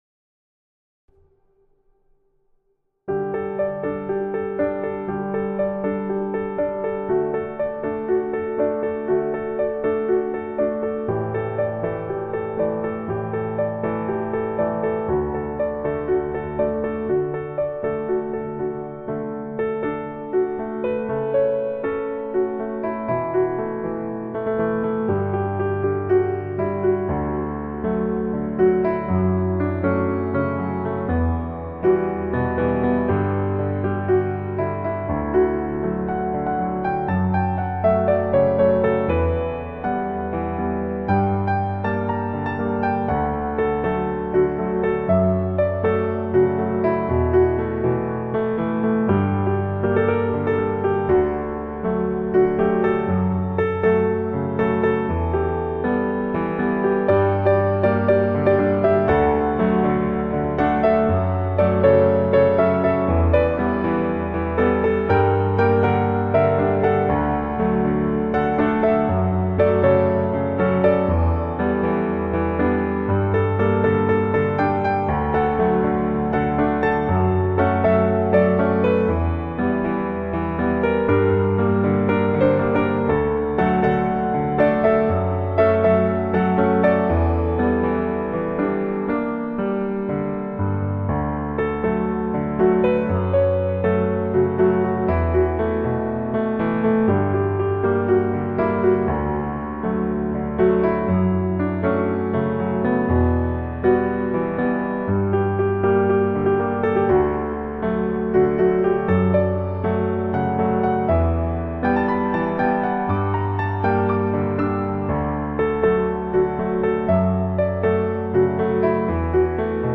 piano cover